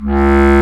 Index of /90_sSampleCDs/Roland L-CDX-03 Disk 1/WND_Lo Clarinets/WND_CB Clarinet